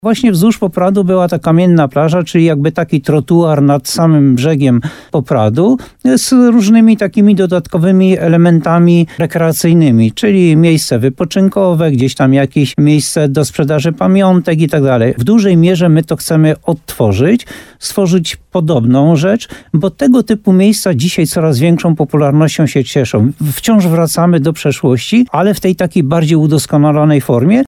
– Przed laty kamienna plaża była hitem dla turystów – mówi burmistrz Jan Golba.